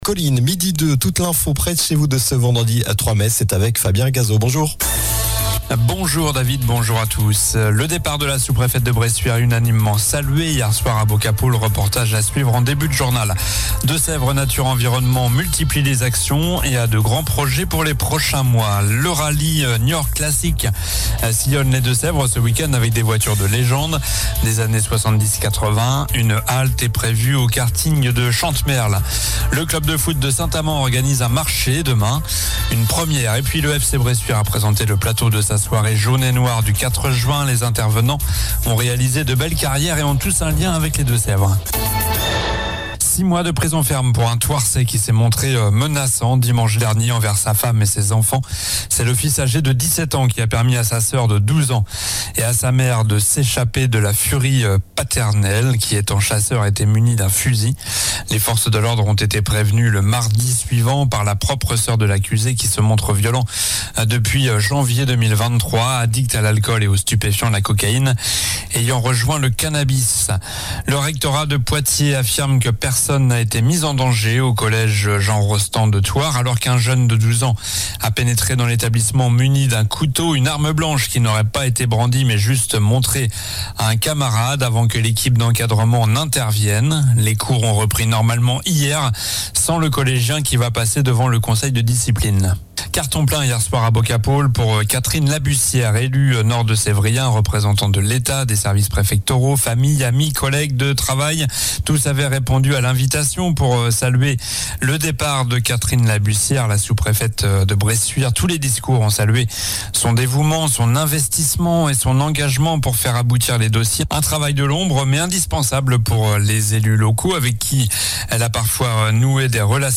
Journal du vendredi 03 mai (midi)